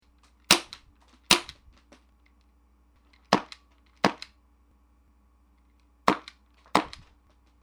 下のＭＰ３は、サイレンサー無し２発　ノーマルサイレンサー２発　Ｏリング入り２発
Ｐ９９ＦＳ　発射音 　※ＭＰ３　１２０ＫＢ
マイクは超定番のシュアーＳＭ５８、インターフェイスはローランドのＵＡ−３０です。
銃本体の銃口からの距離を５０ｃｍに固定して録音
音量的には思った程の差はなく、サイレンサーの有無に因る差異は
２０Ｈｚ〜１５００Ｈｚの低音域では、逆にサイレンサーを付けた方が大きくなっています。